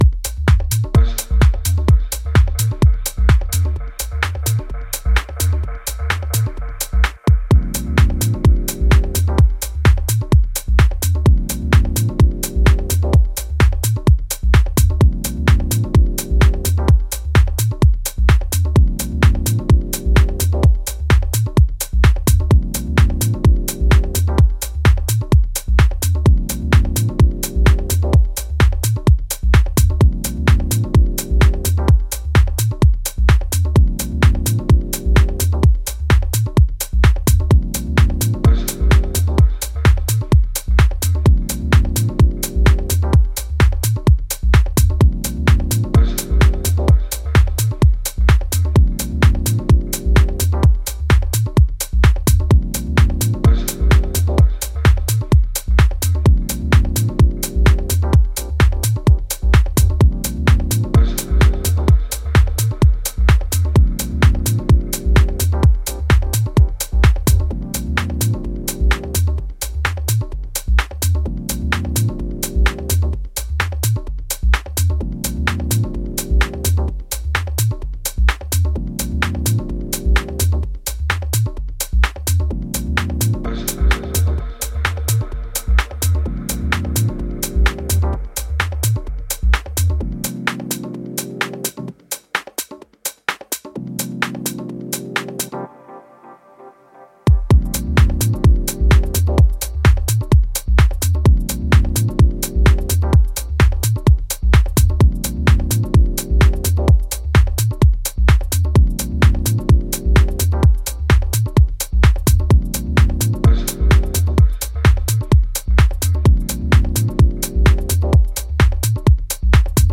House reveals again its glorious aesthetic splendor.